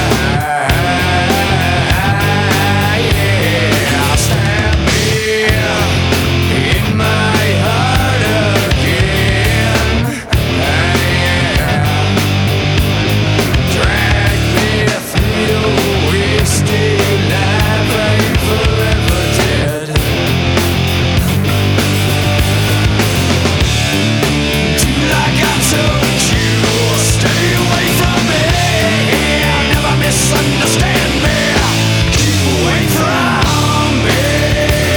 Жанр: Рок
Hard Rock